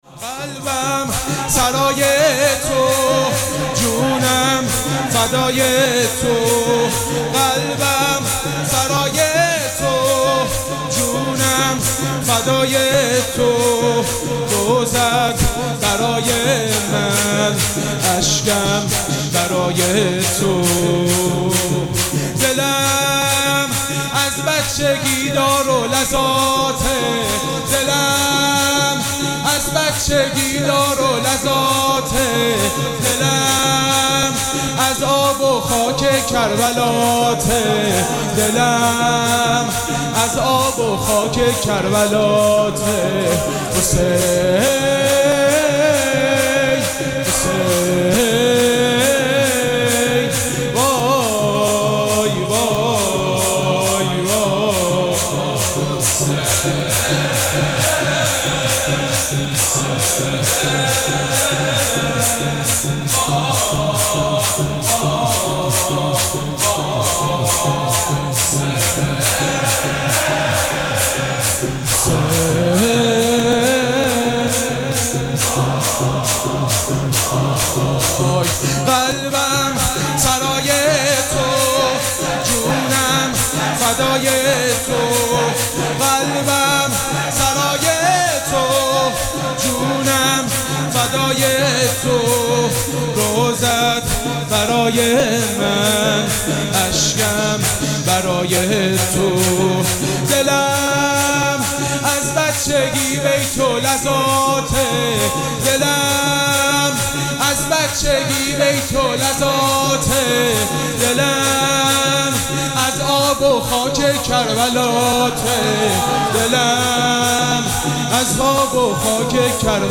مراسم عزاداری شب نهم محرم الحرام ۱۴۴۷
شور
مداح